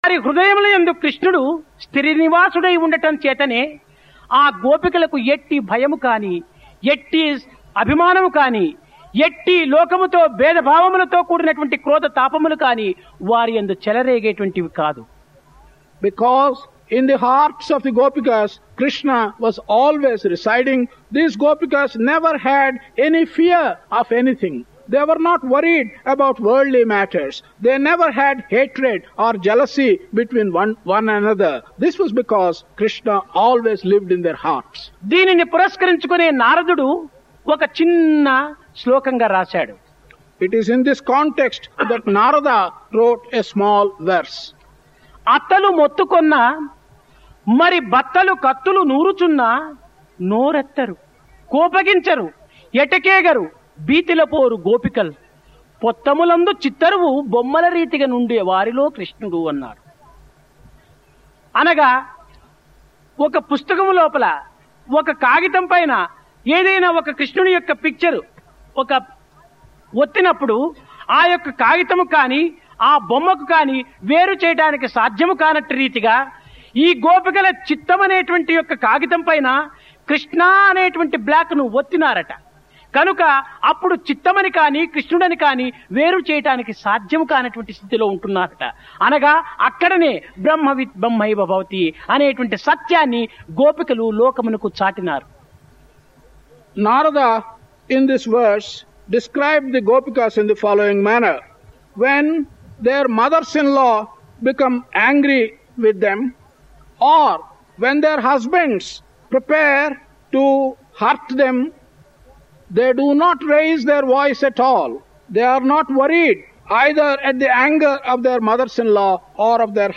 1973年6月1日のサマーコースの講話より） 講話の中のゴピカとは、クリシュナが育った村（ブリンダーバン）のゴピカたちである。